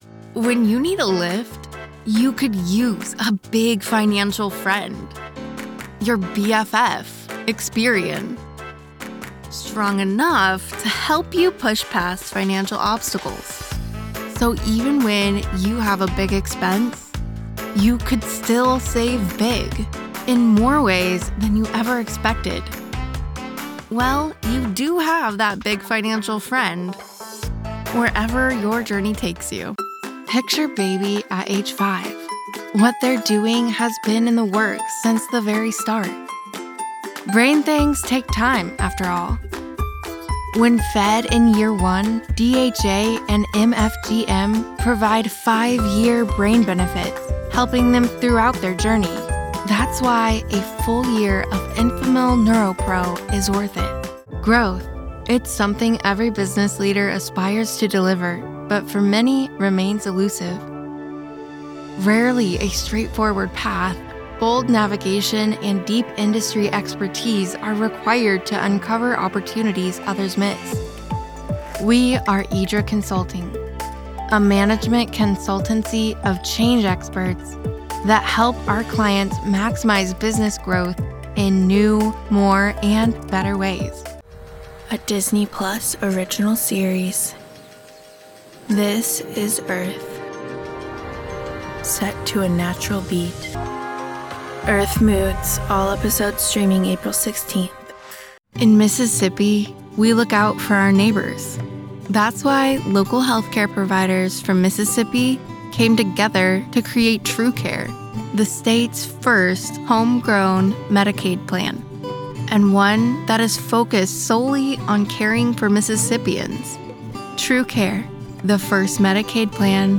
Relatable, conversational, real person, believable, soft, warm, sincere, sensual, Persuasive, Friendly, relatable, smooth, funny, quirky, deadpan, narrator, informational, explainer, advertisement
Full-time female American voice actor with soft